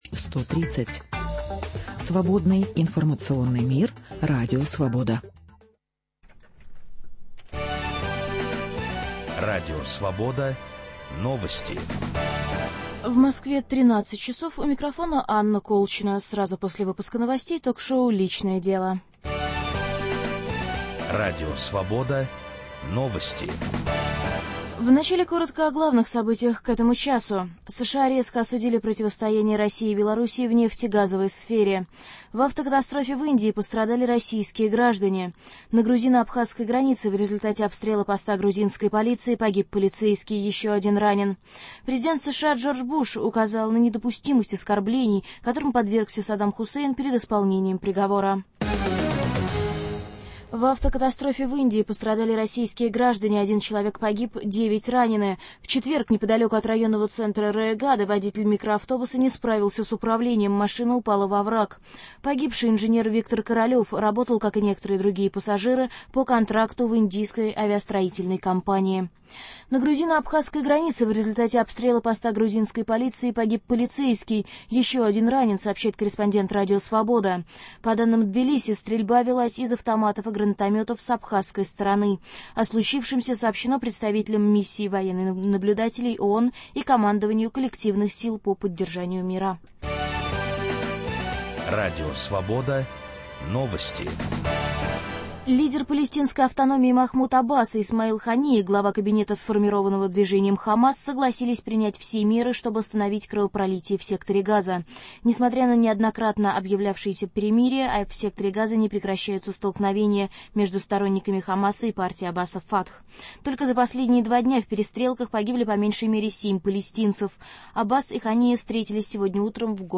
Эксперт: американский клинический психотерапевт